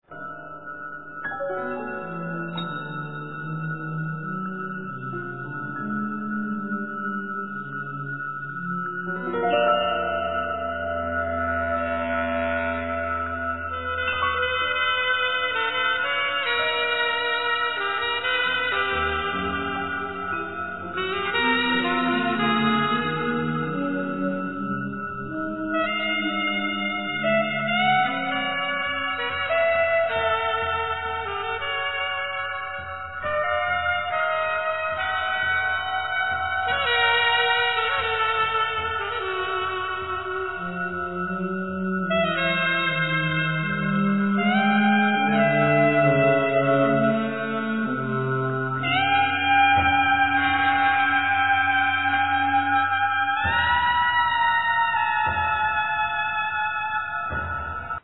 Saxes, Flutes
Clarinets
Piano, Percussion
Bass
Drums, Percussions